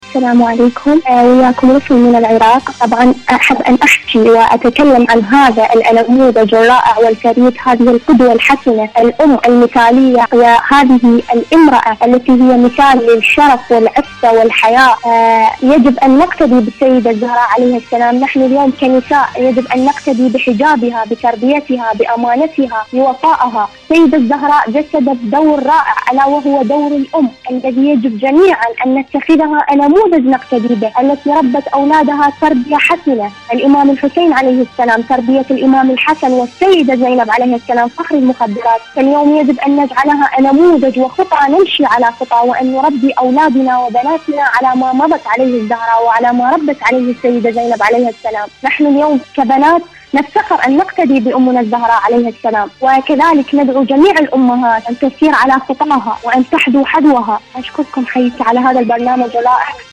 مشاركة واتساب صوتية
إذاعة طهران- المنتدى الإذاعي